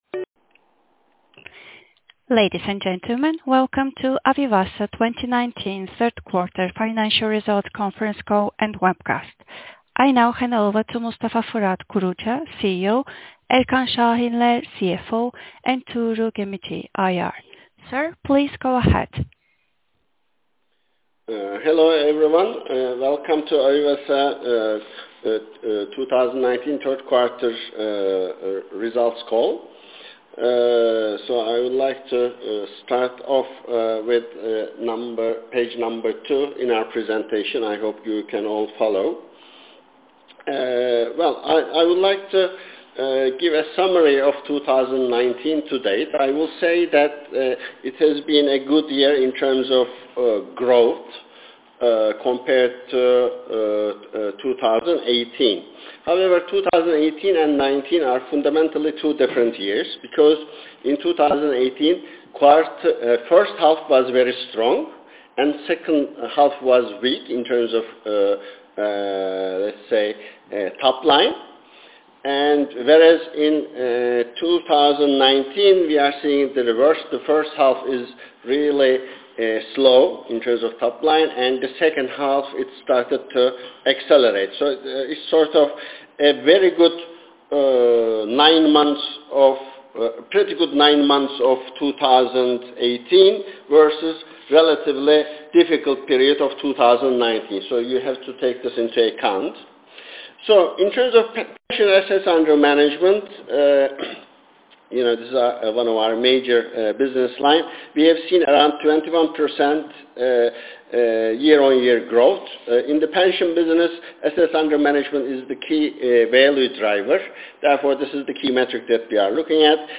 avivasa-2019-q3-financial-results-conference-call.mp3